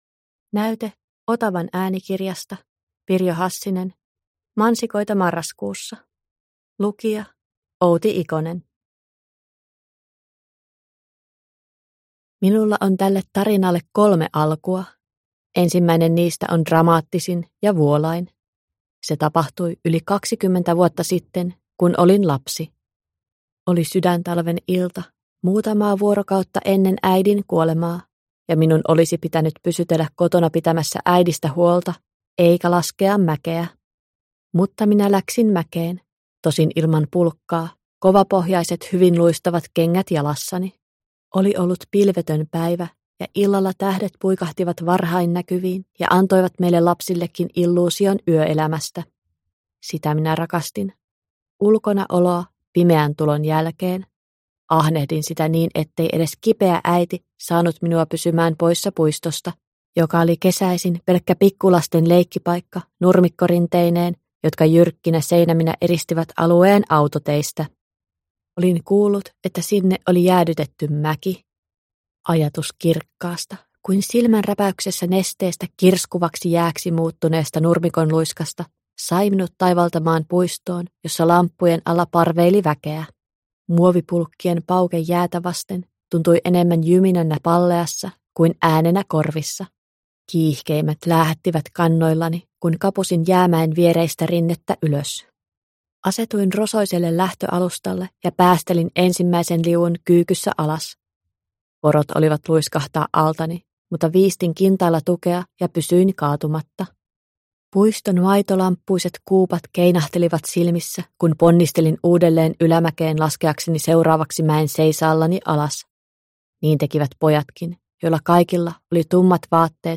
Mansikoita marraskuussa – Ljudbok – Laddas ner